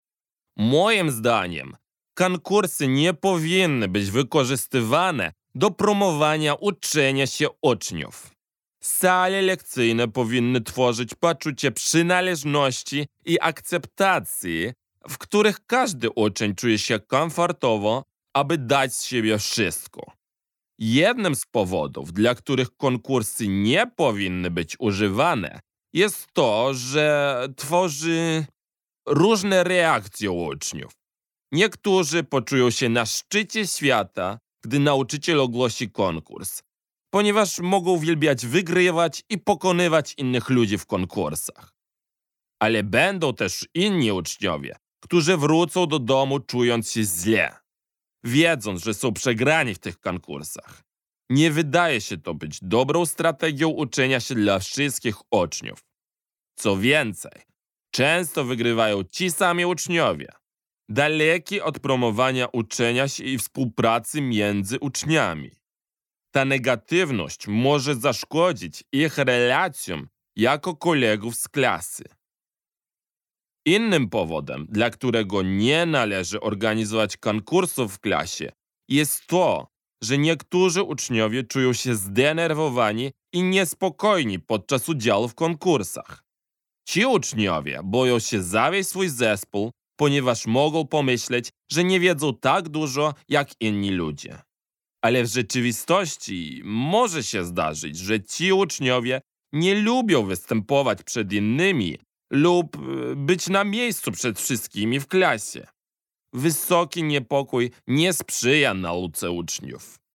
[Note: In the transcript below, two dots indicate that the speaker paused. The three-dot ellipsis indicates that the speaker omitted text when quoting from the article. Errors in emphasis are indicated in brackets.]